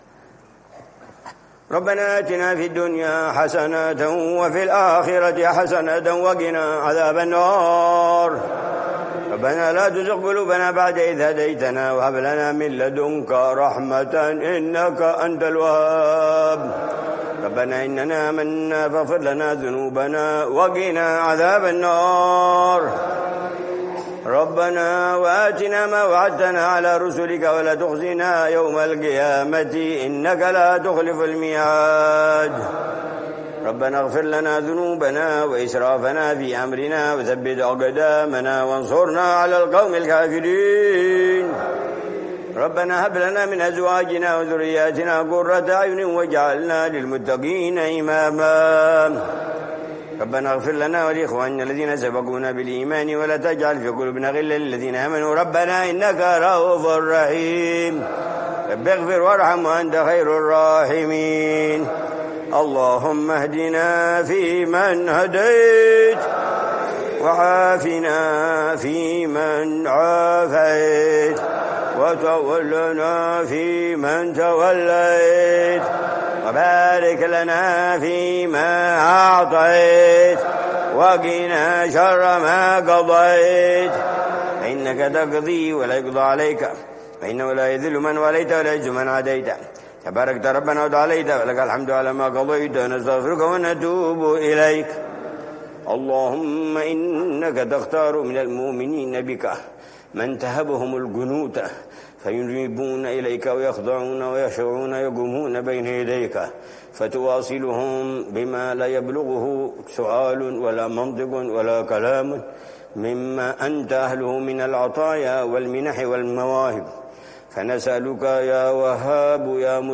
دعاء ليلة 18 رمضان 1446 - وقوموا لله قانتين
في قنوت الوتر، ليلة الثلاثاء 18 رمضان 1446هـ ( وقوموا لله قانتين )